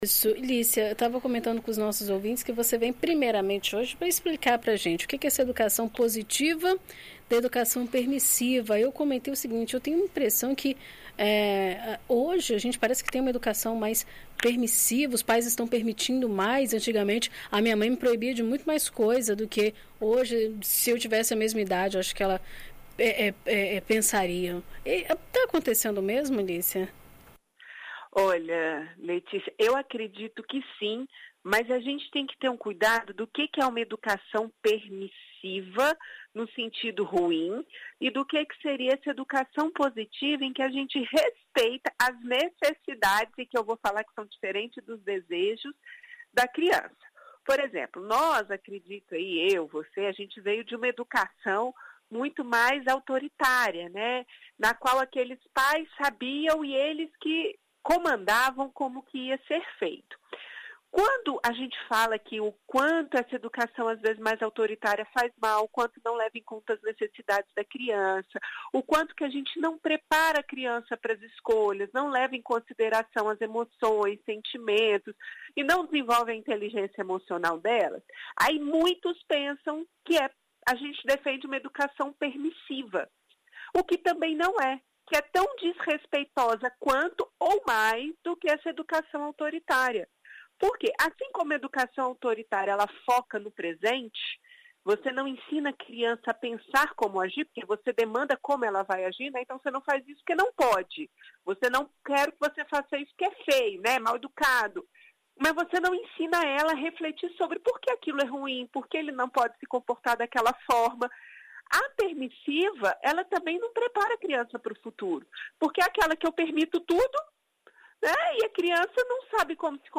Em entrevista à BandNews FM Espírito Santo desta segunda-feira